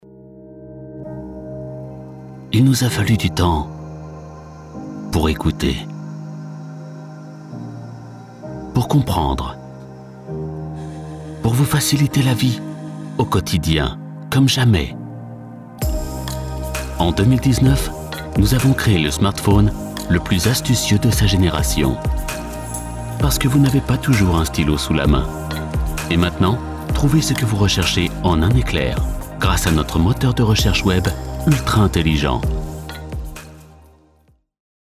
Démo commerciale
Narration
Apprentissage en ligne
Concernant mon matériel, je possède une cabine vocale et le légendaire micro Neumann U87.